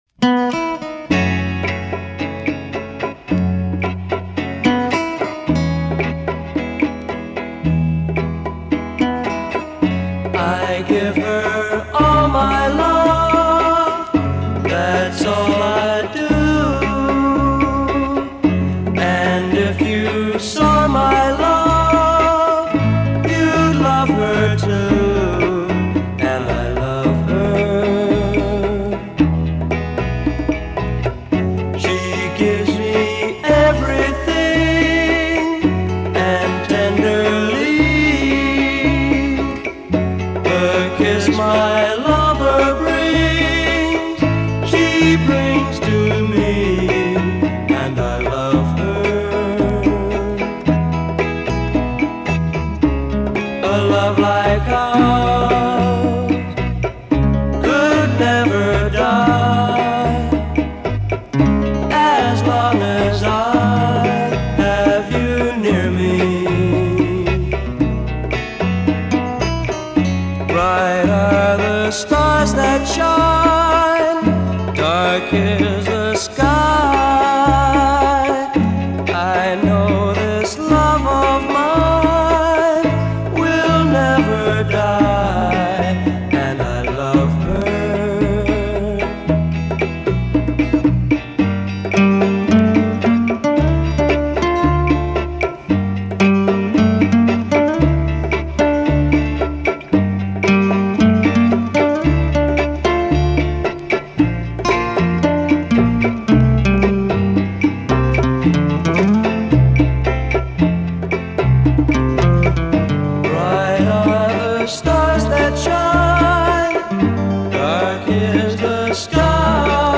Очень близко к оригиналу.